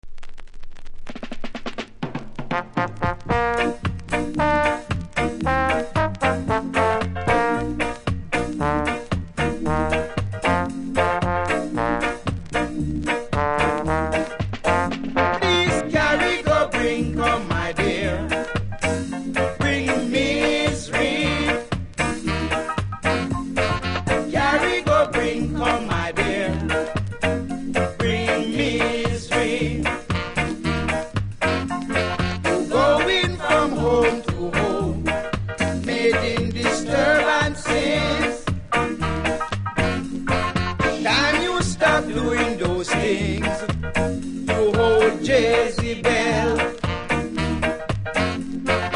キズそこそこありますがノイズは少なく気にならない程度。